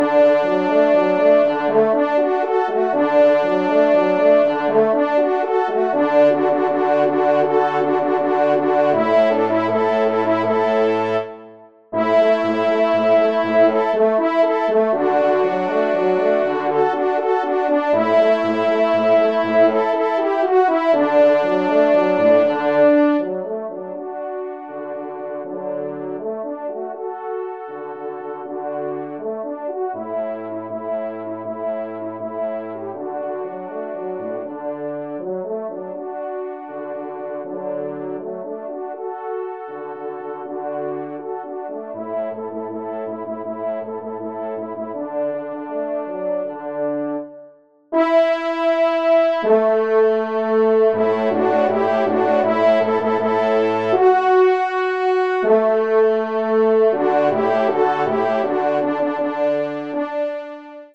5e Trompe